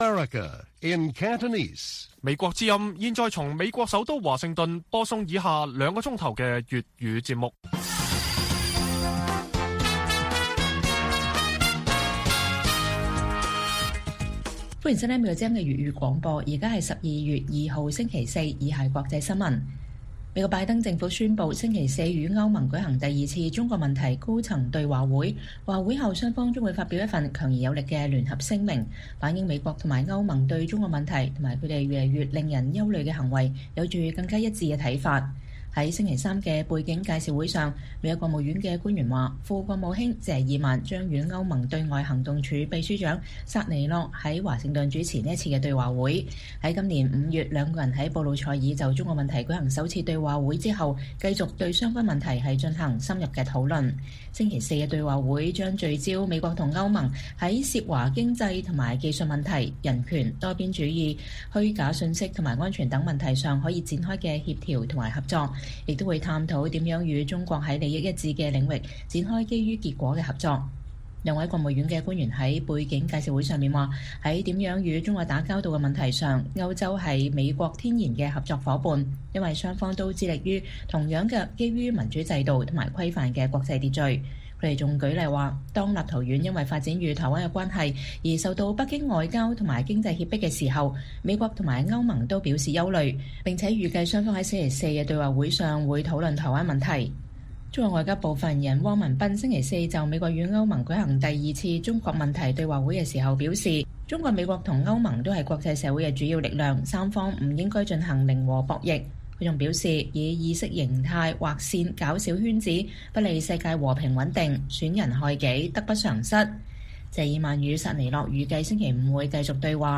粵語新聞 晚上9-10點: 美國與歐盟舉行第二次中國政策協調對話會